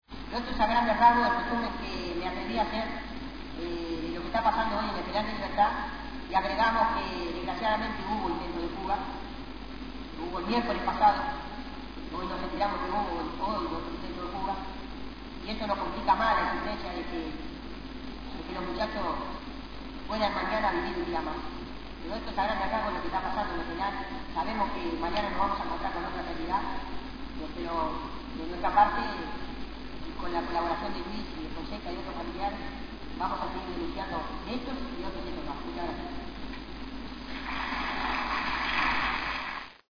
Comenzó leyendo un comunicado emitido por Familiares de Presos en Lucha y el Comité por la Libertad de los Muchachos Presos. Concluyó desarrollando ampliamente la situación que viven los presos sociales en los campos de concentración, especialmente en las ruinas del Penal de «Libertad».